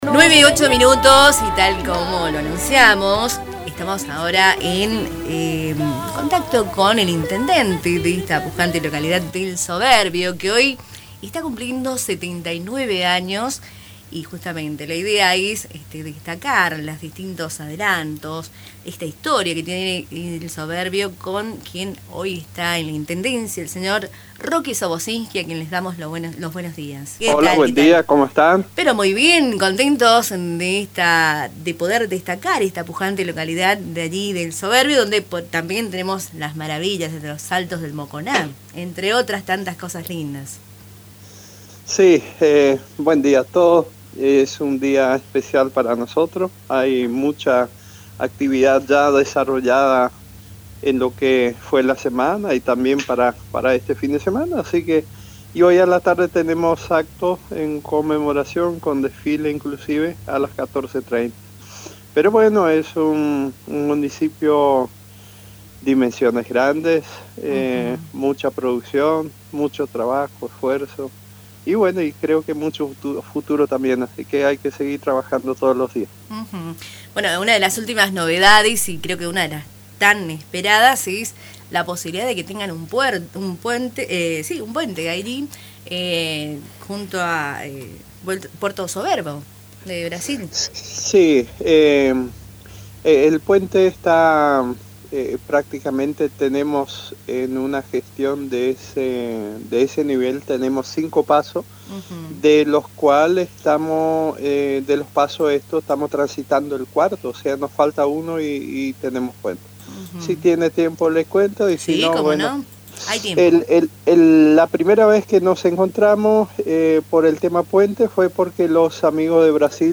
Roque Soboczinski, intendente de El Soberbio, resaltó los avances del municipio y el ambicioso proyecto del puente binacional que unirá El Soberbio (Argentina) con Porto Soberbo (Brasil), durante una entrevista en el programa Cultura en diálogo, emitido por Radio Tupa Mbae.